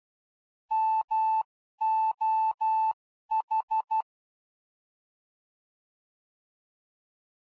Fox hunt beacons are identified by morse code signals.
Morse code identifiers of the foxes
4 MOH — — — — — ∙ ∙ ∙ ∙ [ogg][mp3]